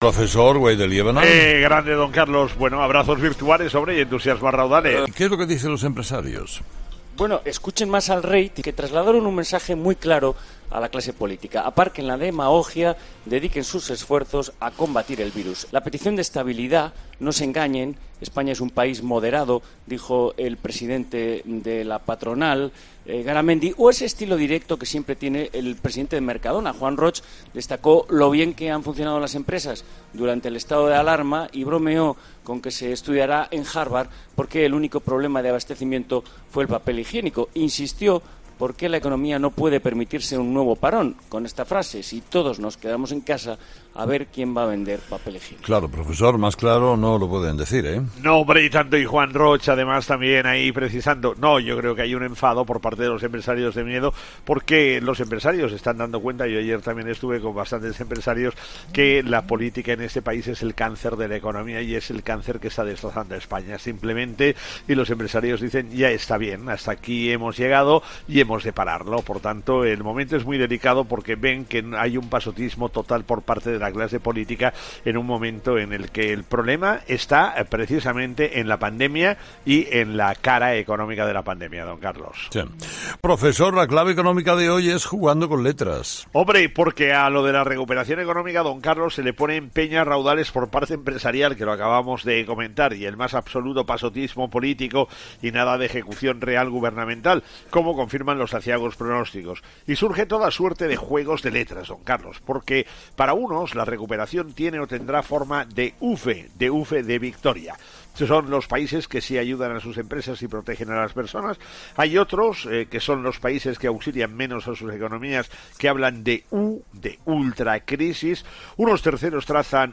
La actualidad económica en 'Herrera en COPE' con el profesor Gay de Liébana.